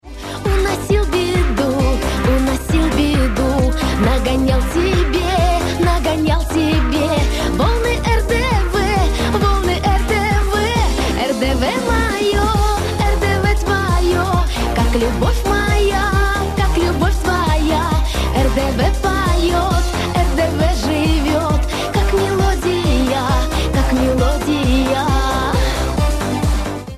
Заставочки